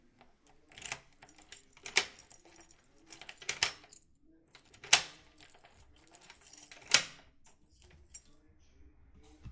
用钥匙开锁的门
描述：解锁并锁上门
Tag: 解锁 钥匙 解除 锁定 钥匙